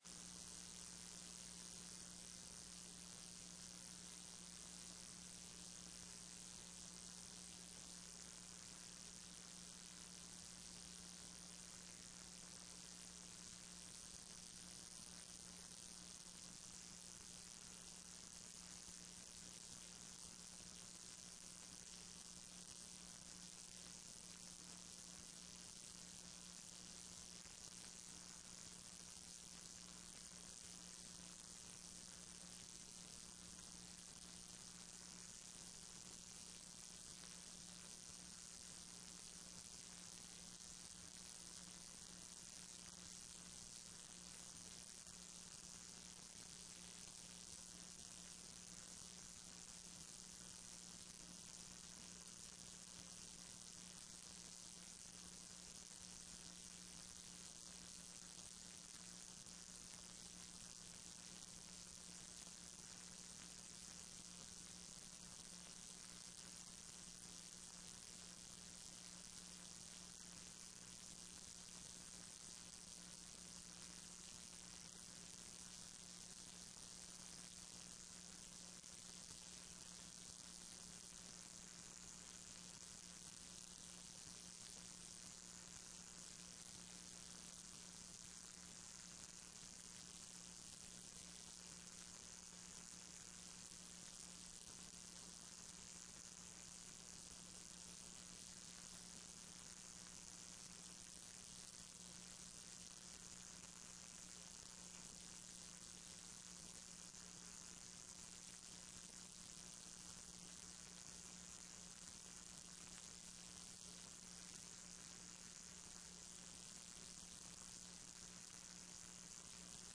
TRE-ES sessão do dia 15 09 14